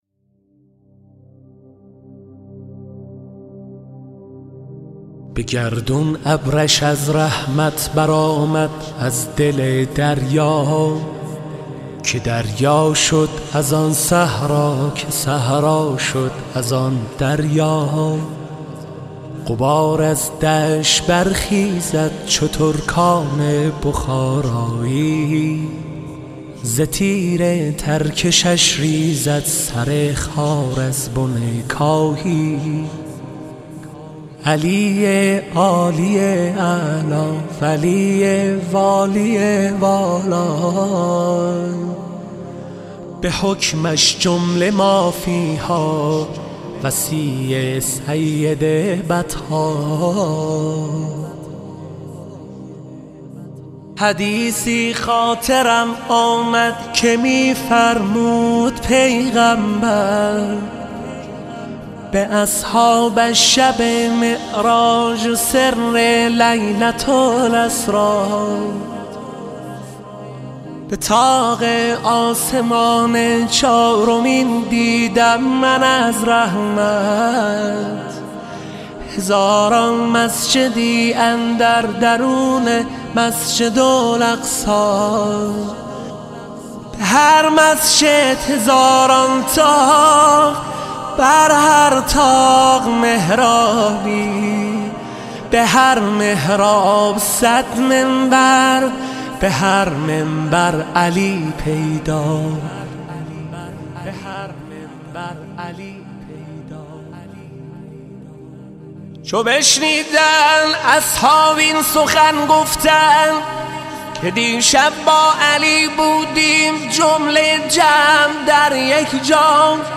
خیمه گاه - هیئت نوجوانان خیمة الانتظار زنجان - نماهنگ به گردون ابرش از رحمت(امام علی)